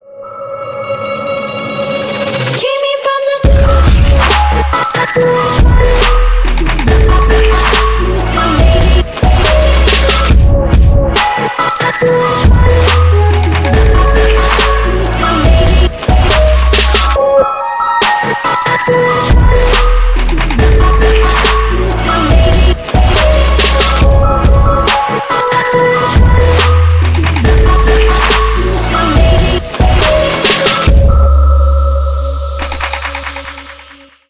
Ремикс # Электроника
громкие